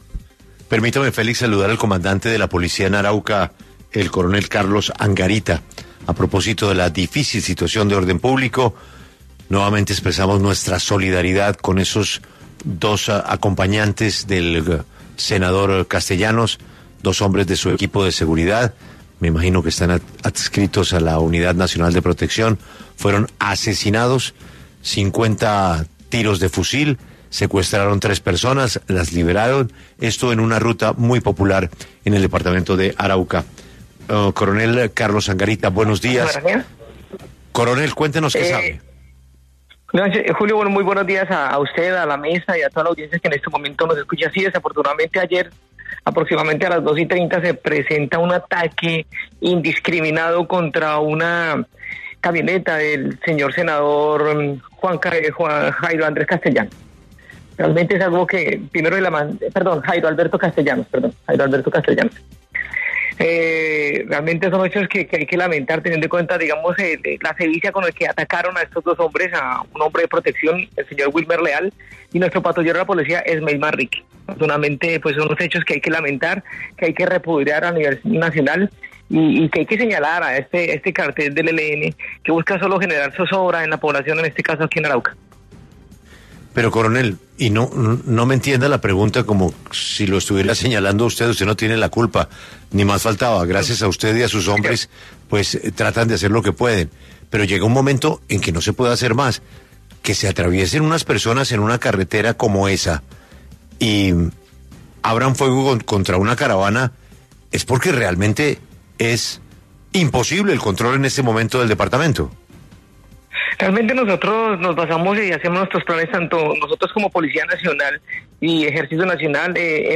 En entrevista con 6AM W de Caracol Radio, el Teniente Coronel Carlos Angarita, Comandante del Departamento de Policía Arauca, condenó el ataque indiscriminado ocurrido ayer, 5 de febrero, contra el esquema de seguridad del senador del partido Alianza Social Independiente (ASI), Jairo Alberto Castellanos.